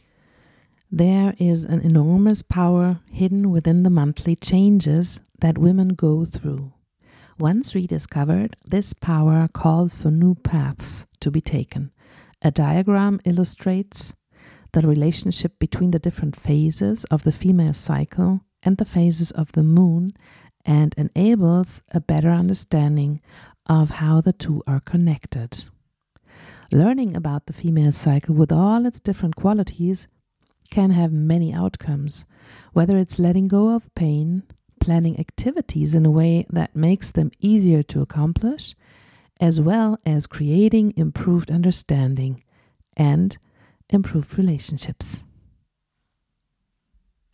moonbook-reading-sample.mp3